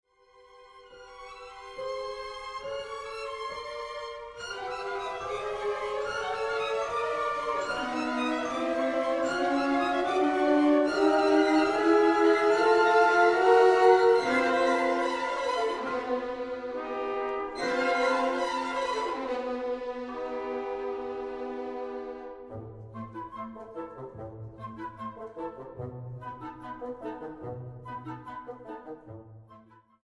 Grabado en: Teatro Aguascalientes, marzo, 2013.